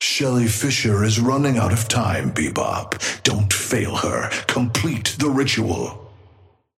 Patron_male_ally_bebop_start_02.mp3